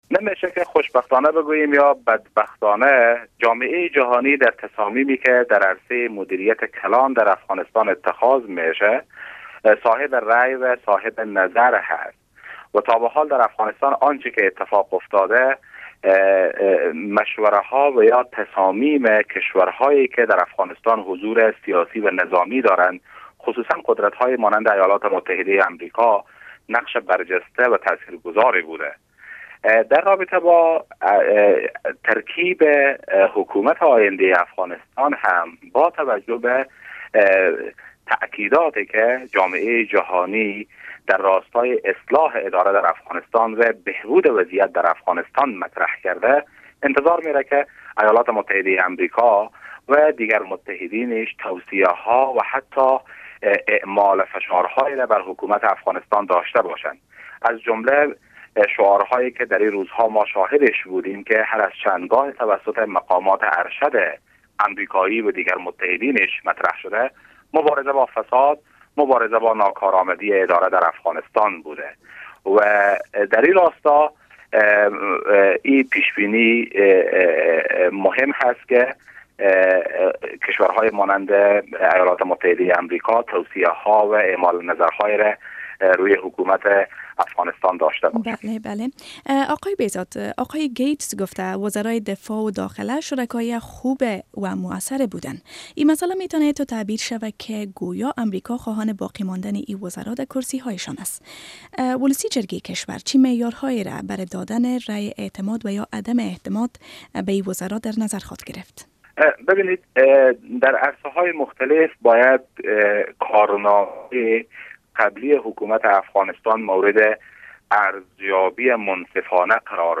مصاحبه با احمد بهزاد عضو ولسی جرگهء افغانستان در مورد دیدار ناگهانی گیتس از کابل